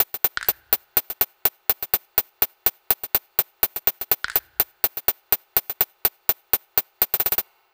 Bleep Hop Cr78 Hat Loop.wav